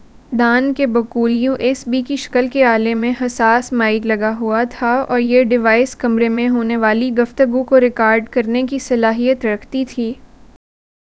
deepfake_detection_dataset_urdu / Spoofed_TTS /Speaker_05 /114.wav